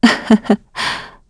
Isolet-Vox_Happy2_kr.wav